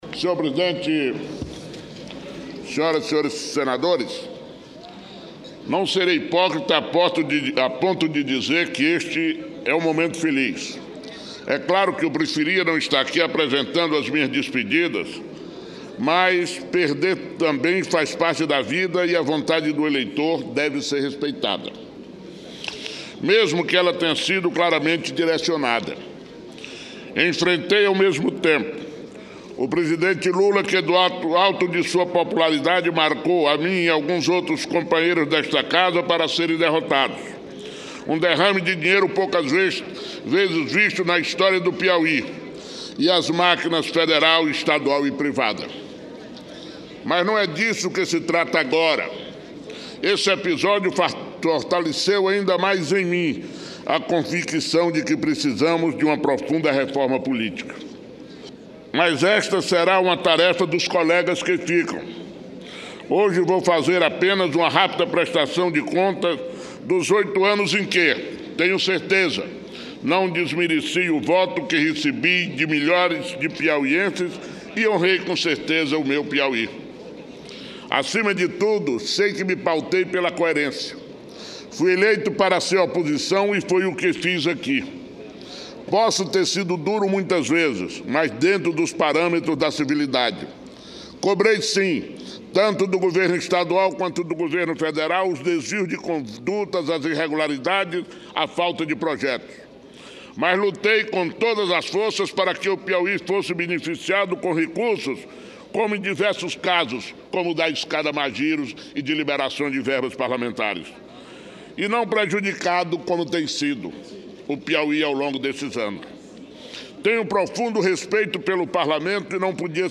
Heráclito Fortes faz pronunciamento de despedida
Tópicos: Pronunciamento